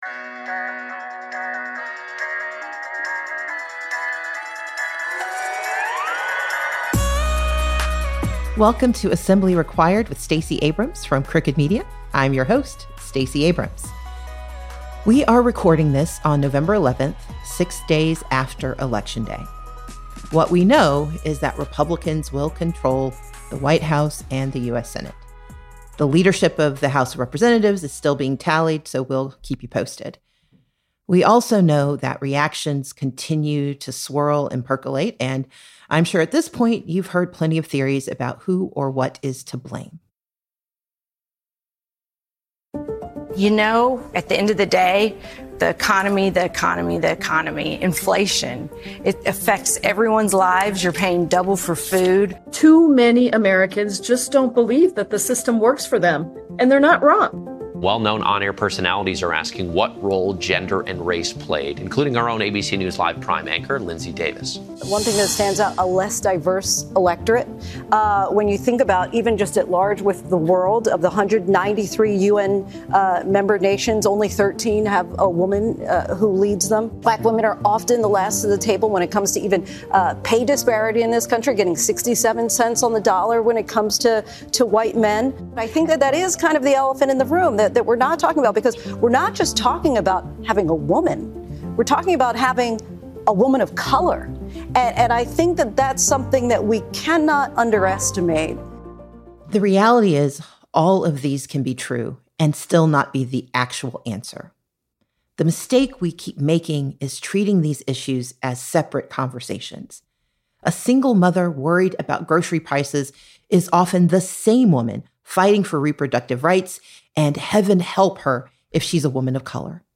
Stacey speaks with historian Heather Cox Richardson to see what history can teach us about moving forward after Trump’s reelection.
They discuss strategies for countering disinformation, how Democratic leaders are preparing to use states’ rights to their advantage to challenge Trump’s federal overreach, and how the era following William McKinley’s presidency can be a guide for progressives. Then, Stacey answers questions from the audience on how to get involved in politics, and how to respond to community in this post-election environment.